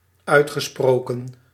Ääntäminen
IPA: /lɛ̃.pid/